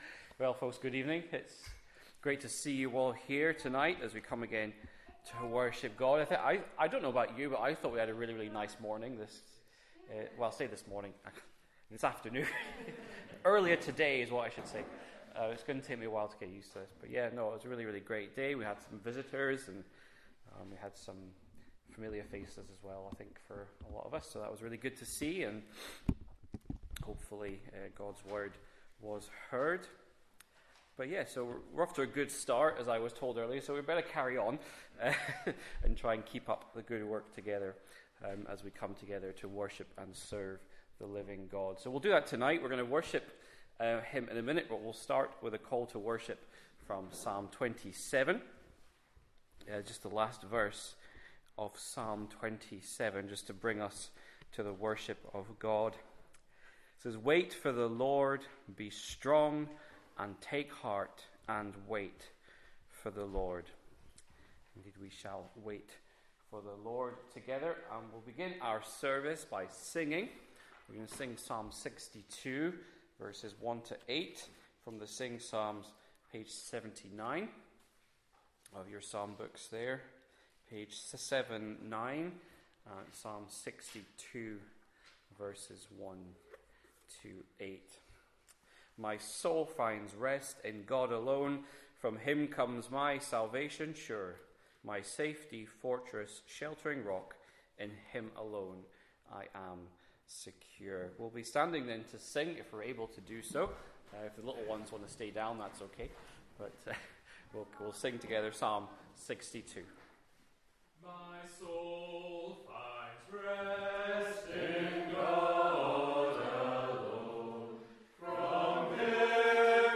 SUnday Service 6pm